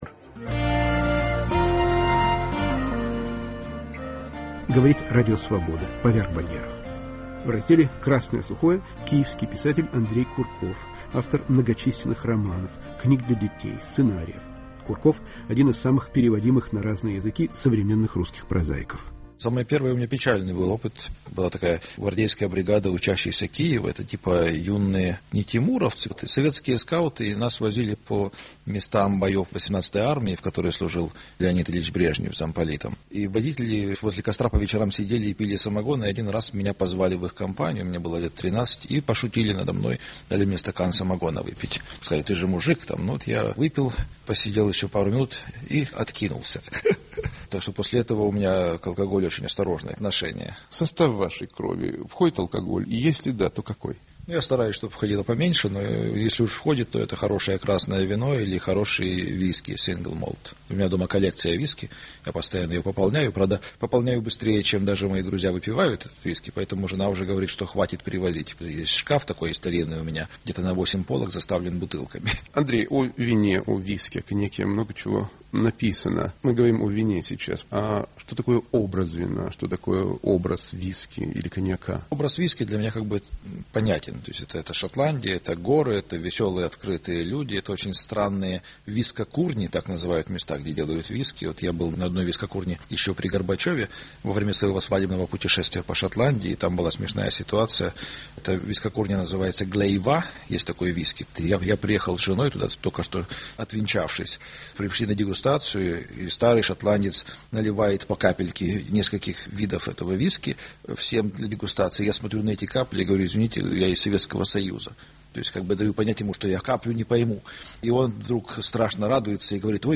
"Красное сухое": беседа с популярным писателем Андреем Курковым, гостившим в усадьбе французского семейства Hennessy